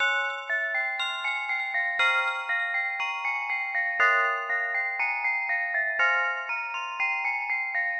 标签： 120 bpm Trap Loops Bells Loops 1.35 MB wav Key : C Logic Pro
声道立体声